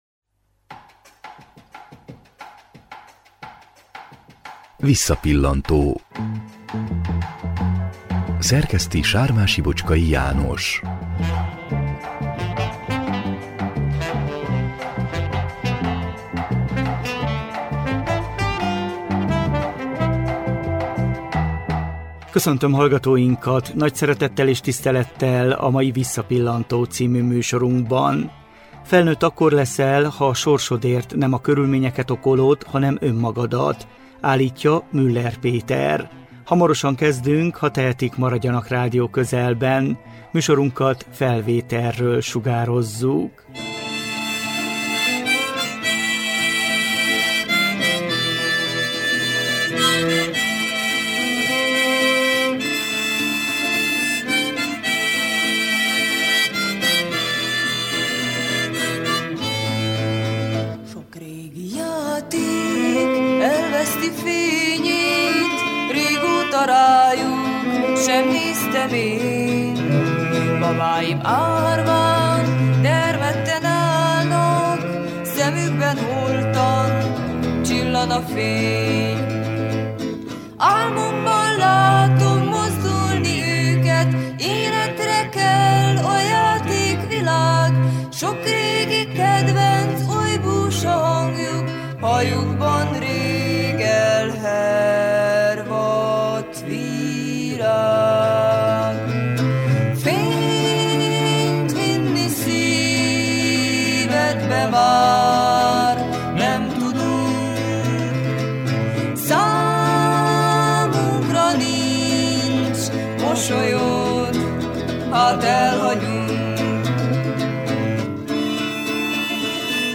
Erdélyben elsők között alakult a székelyudvarhelyi Mc` Factory dance együttes. Zenés beszélgetésünk 1999 őszén készült.